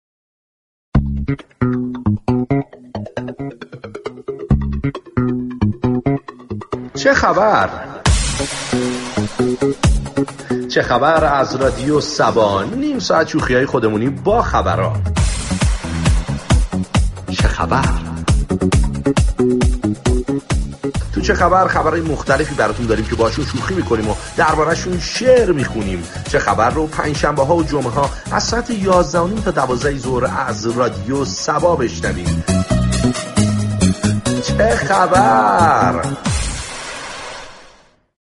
به گزارش روابط عمومی رادیو صبا ، «همپای صبا » از مسابقات پر هیجان این شبكه است، كه به صورت زنده و از طریق تعامل با مخاطبان به معرفی نقاط گردشگری سراسر ایران می پردازد.